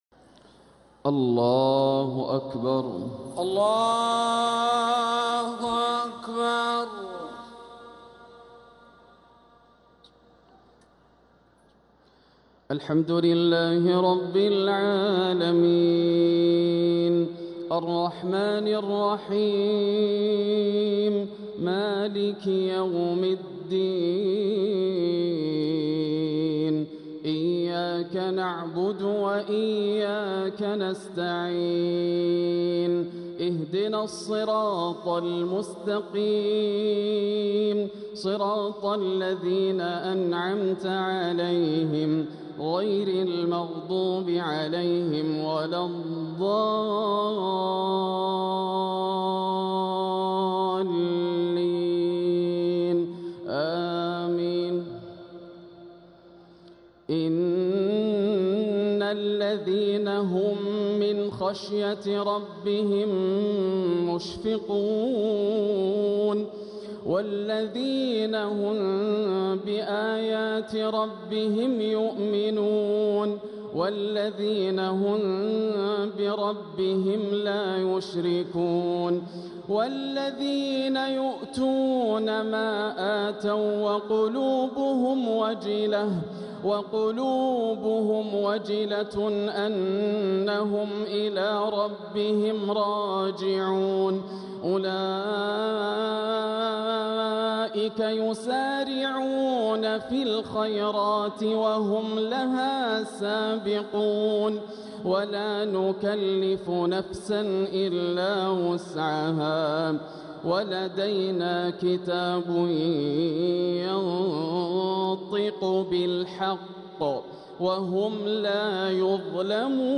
تلاوة من سورتي المؤمنون و الشورى | عشاء الجمعة 28 رمضان 1446هـ > عام 1446 > الفروض - تلاوات ياسر الدوسري